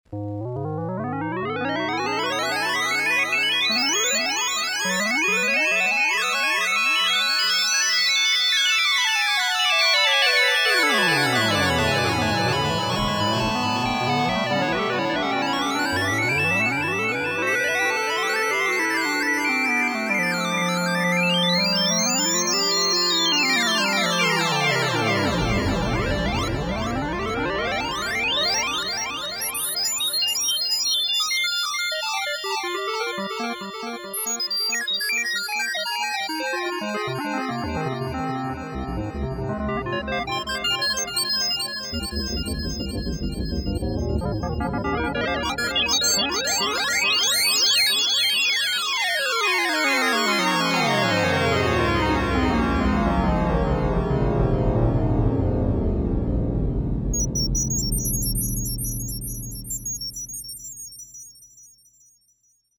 Since the music is in stereo, listening with high quality headphones or through a good home stereo system is advised.
Polyphony of up to five sounds at once can be achieved.
Each of these audio examples were improvised live, and were accompanied by a nice light show!
Spacey.mp3